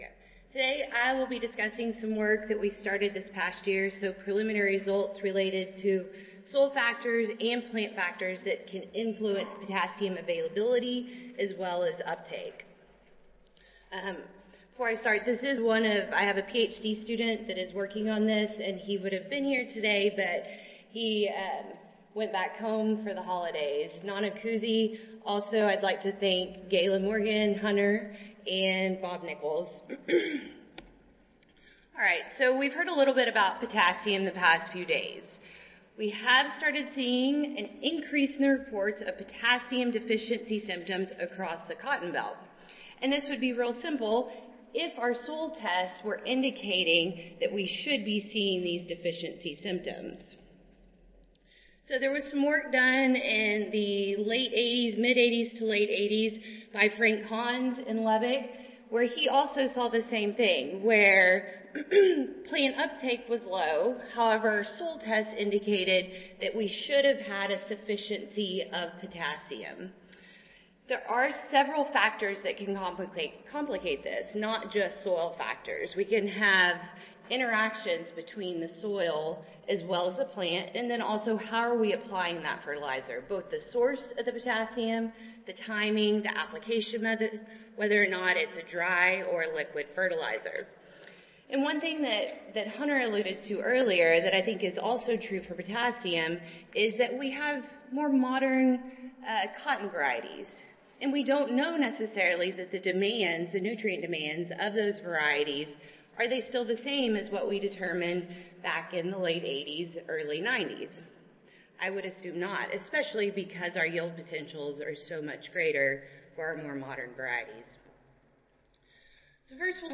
Recorded Presentation Soil factors affecting potassium (K) availability and plant uptake will be discussed. These soil factors include cation exchange capacity (CEC), plant available K at depth, K fixation potential, and soil texture and mineralogy.